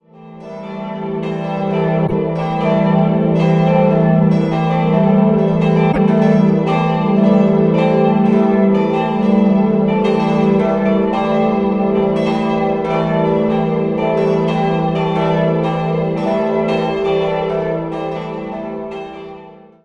Die Ursprünge gehen bis ins 12. Jahrhundert zurück, 1700/02 wurde das Gotteshaus nach Plänen von Wolfgang Dientzenhofer erweitert. 4-stimmiges ausgefülltes E-Moll-Geläute: e'-g'-a'-h' Die Glocken wurden von Friedrich Wilhelm Schilling gegossen.